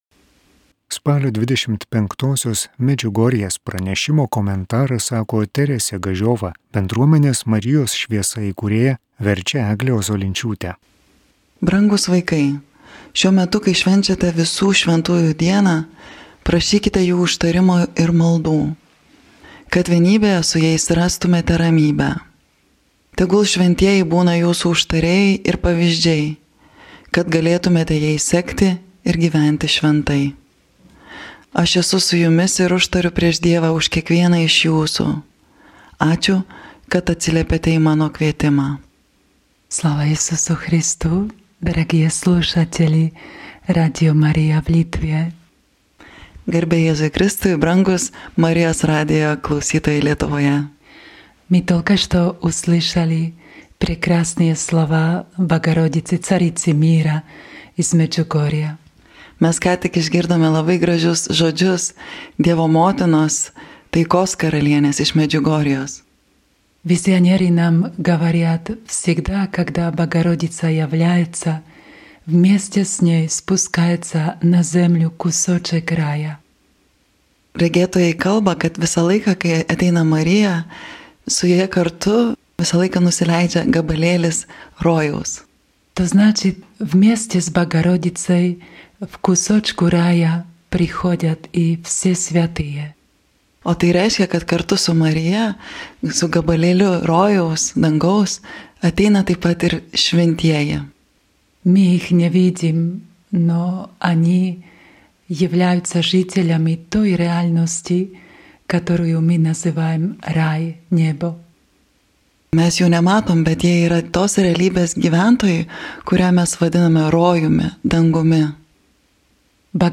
1 Katechezė